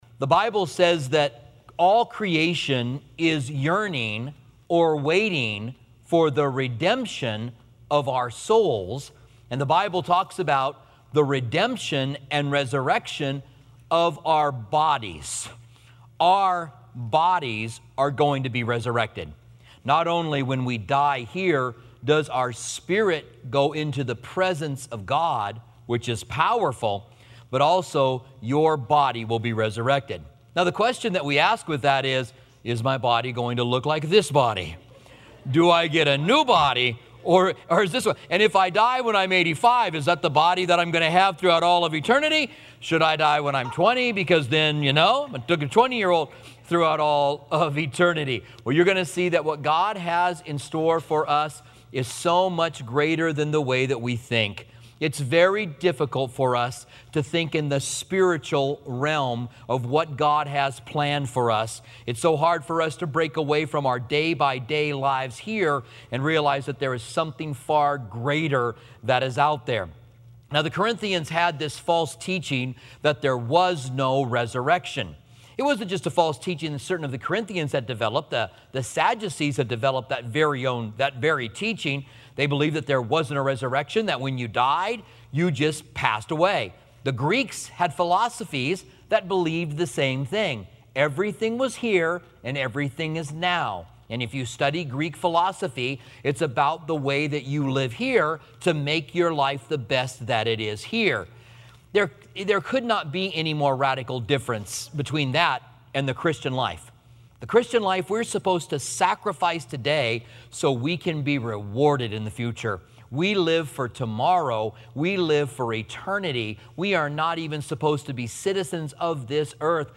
Commentary on 1 Corinthians